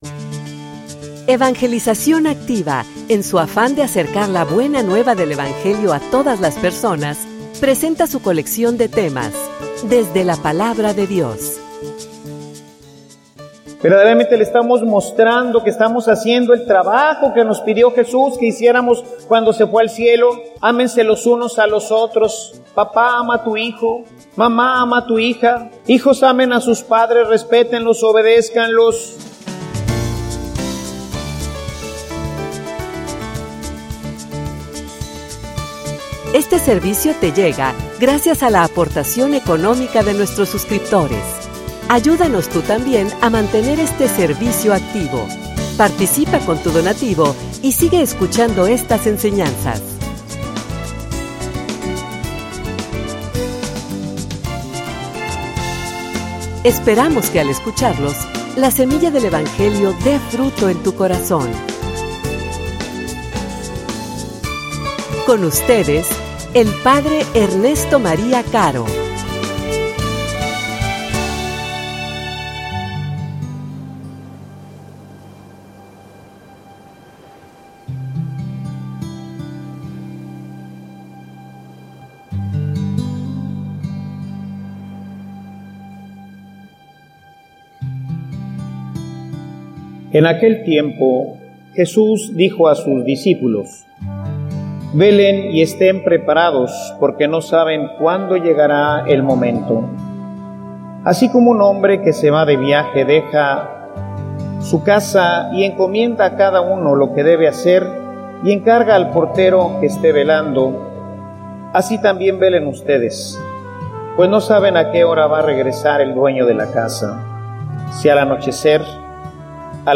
homilia_Preparando_el_encuentro.mp3